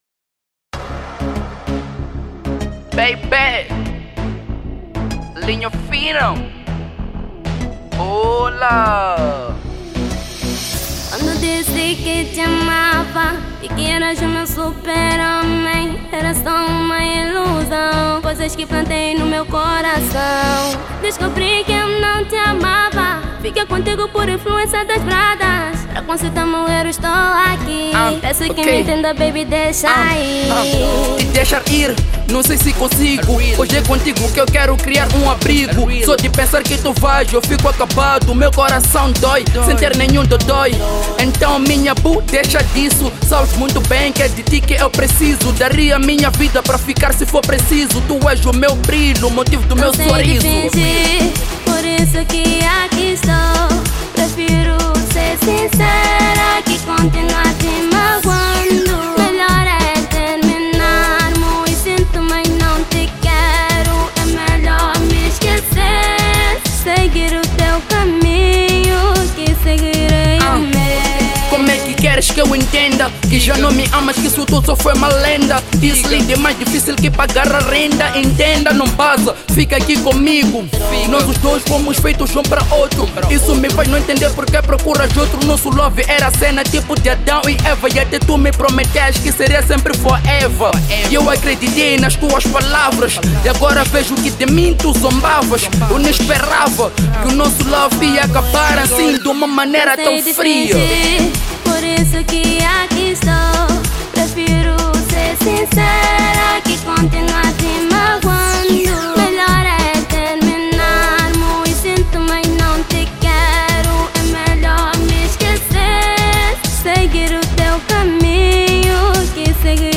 Género: Ragaton